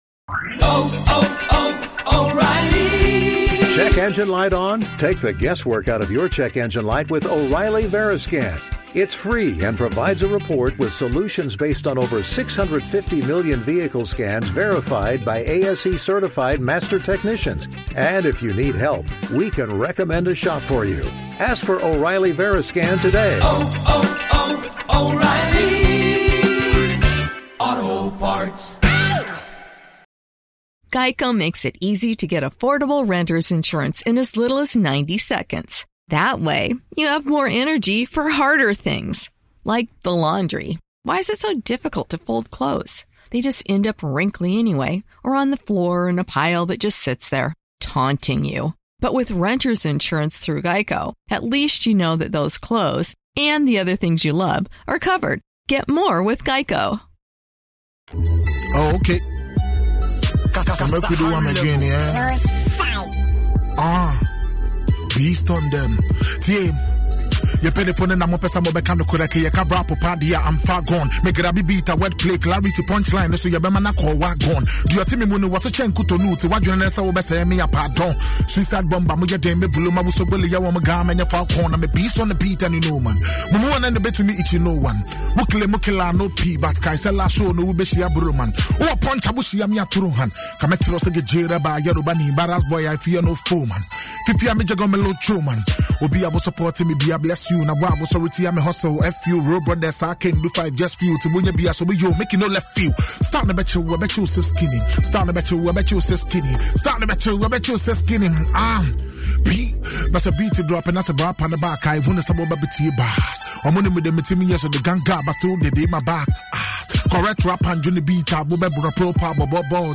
Music show featuring live performances by underground and upcoming musicians